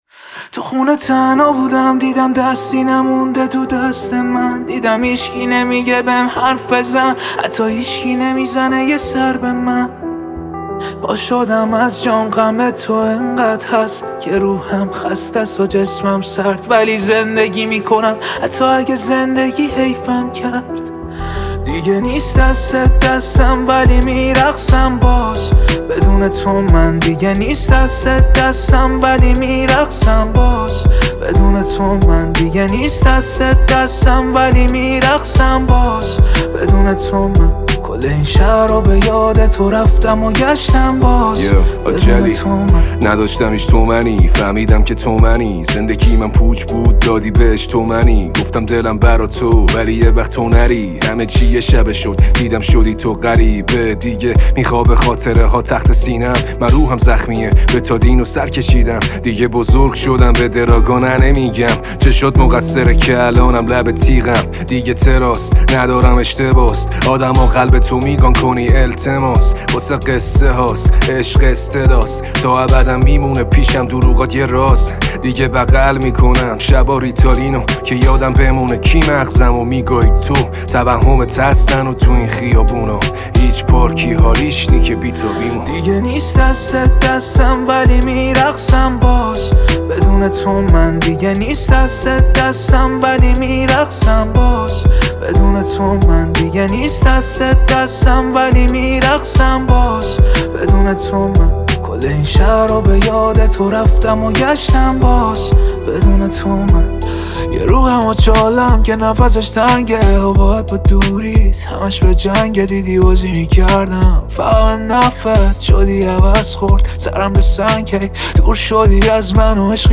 اهنگ جدید رپ